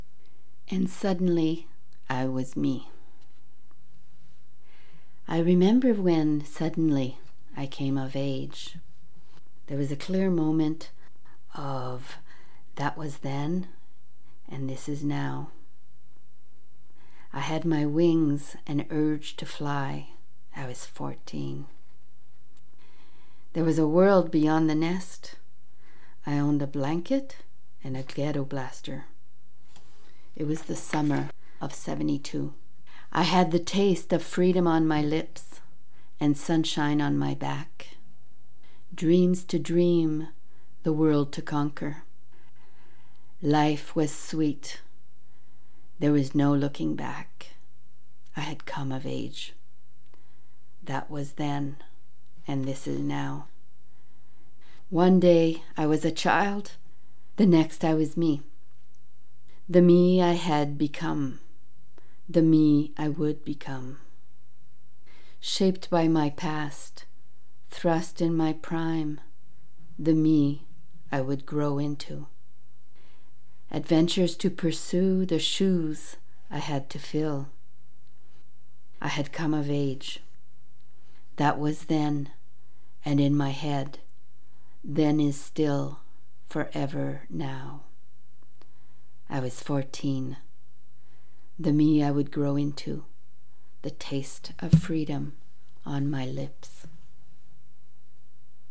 Read on air by invitation  ~  April 8, 2021  'LATE NIGHT POETS'
Wow! So delighted am I to listen to you in your expressive vocal reading of your poem.God bless you.
Excellent -- and a pleasure to hear your reading!